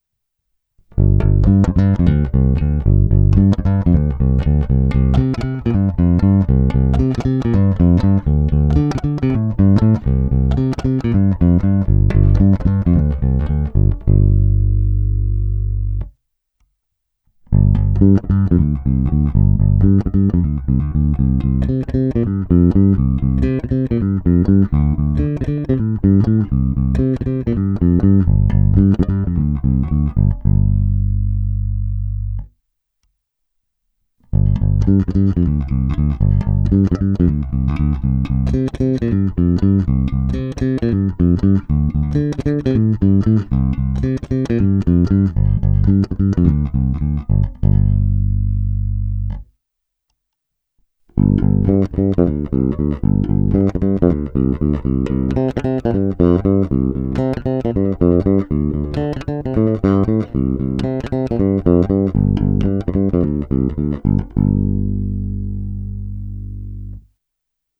Není-li uvedeno jinak, následující nahrávky jsou provedeny rovnou do zvukové karty a s plně otevřenou tónovou clonou. Nahrávky jsou jen normalizovány, jinak ponechány bez úprav.